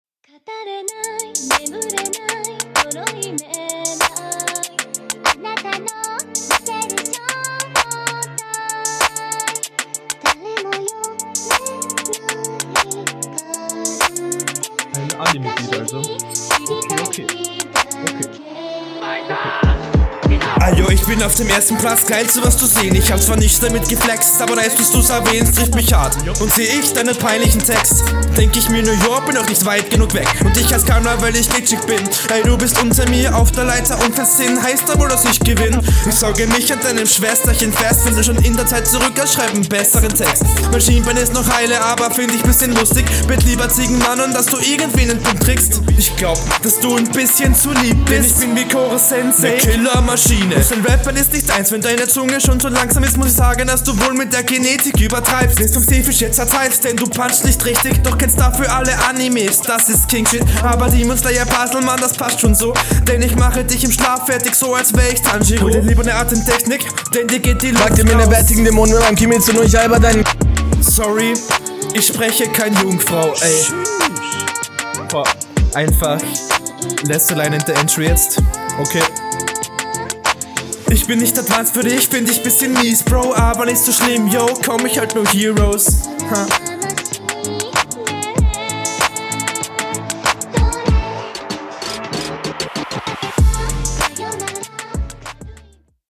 Ich mag den Stimmeinsatz hier relativ gerne, die Delivery ist auch okay.